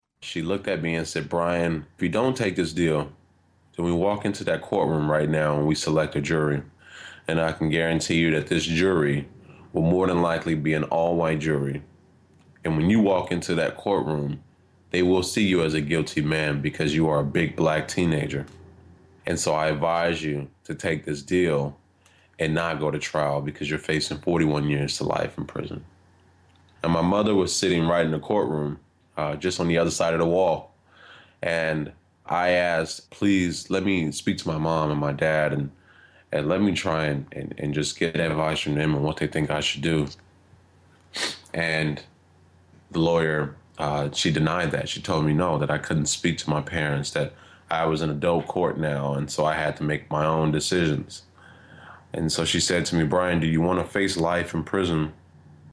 Review 2013. A taste of the most amazing sport interview of the year